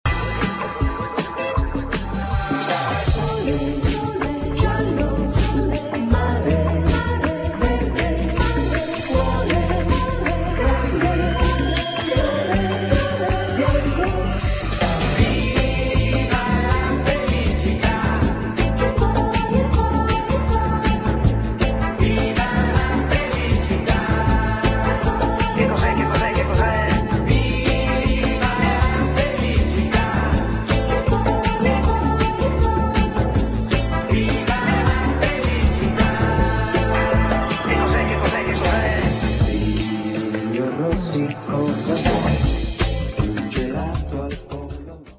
[ SOUNDTRACK / LOUNGE / HOUSE ]